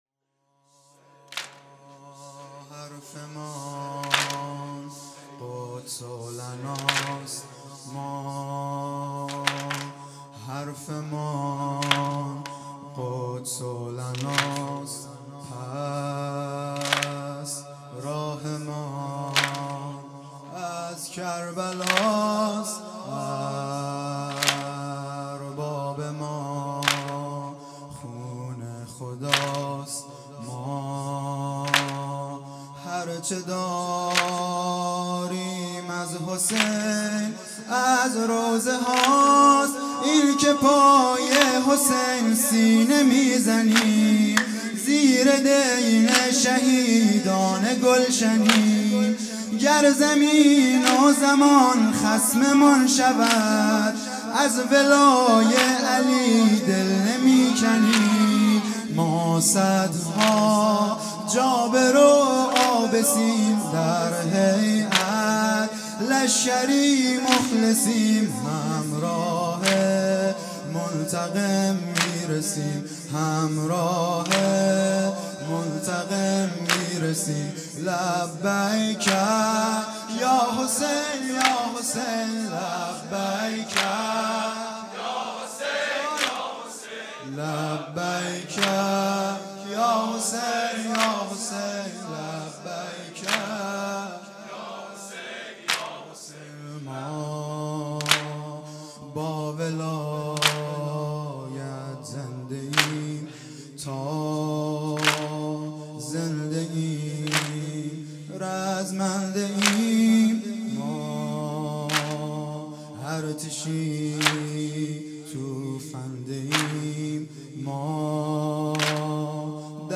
قدس لنا|جلسه هفتگی ۴ آبان ۹۵